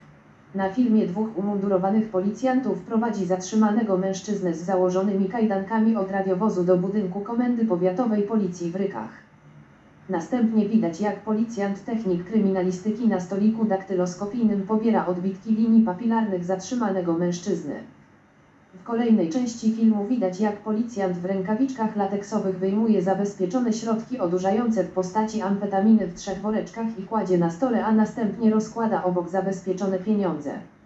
Nagranie audio Audiodeskrypcja_Tymczasowy_areszt_dla_59-latka.m4a